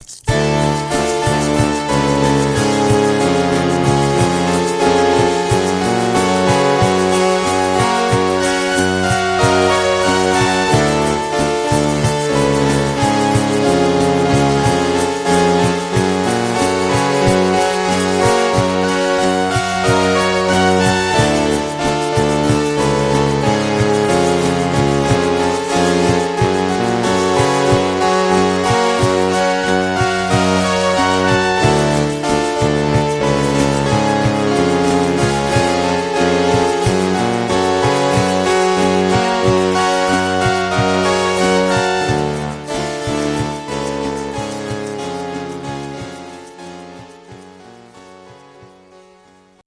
Long Resolve is kinda neato. I recorded it at 150 Mill back in 1995 I think.
Made on a POS Yamaha keyboard from the mid '80's... 3 keyboard parts and a maraca thrown in for good measure.